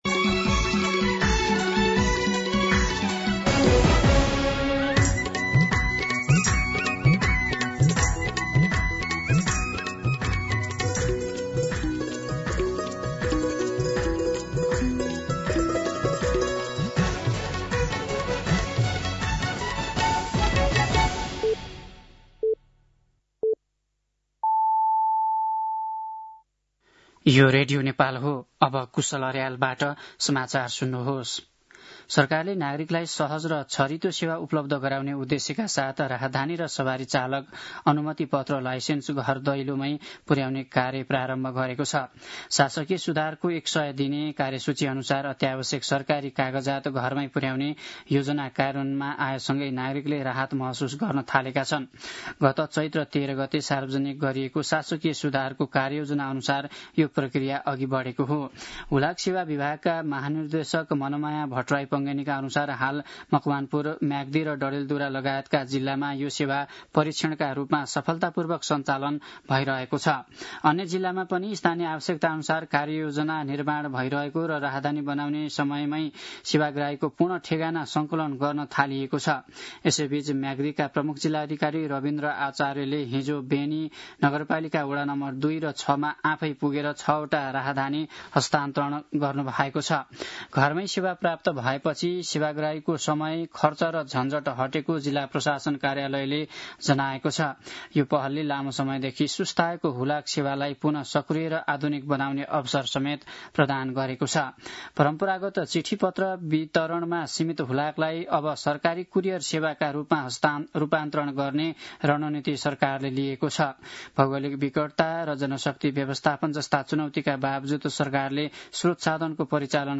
दिउँसो ४ बजेको नेपाली समाचार : ३ वैशाख , २०८३
4-pm-Nepali-News.mp3